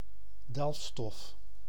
Ääntäminen
Ääntäminen France Tuntematon aksentti: IPA: /mi.ne.ʁal/ Haettu sana löytyi näillä lähdekielillä: ranska Käännös Ääninäyte Substantiivit 1. delfstof {c} 2. mineraal {n} Adjektiivit 3. mineraal {n} Suku: m .